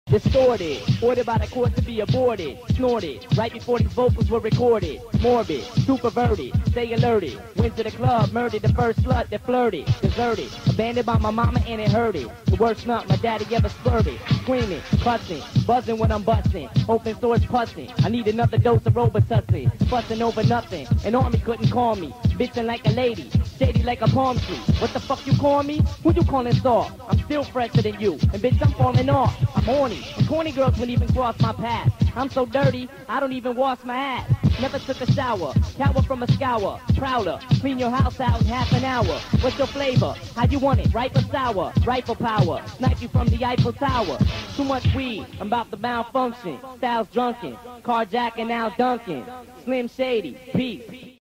freestyle
rap
hiphop